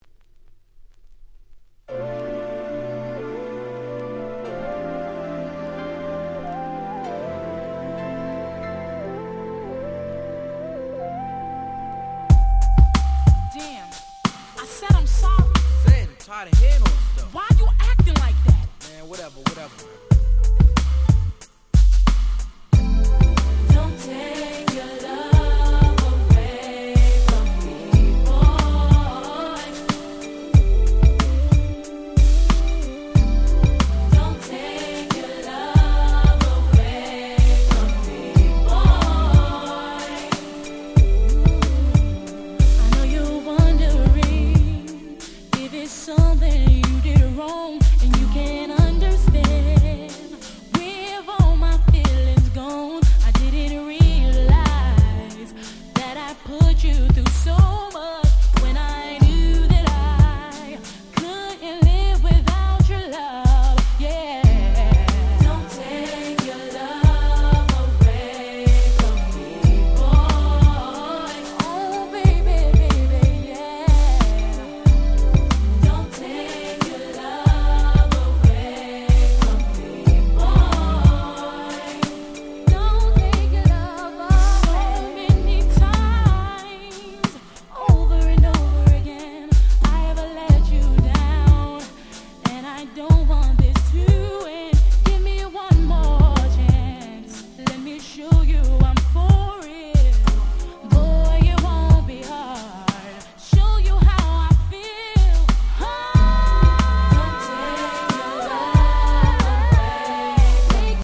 96' Very Nice Slow Jam / R&B / Hip Hop Soul !!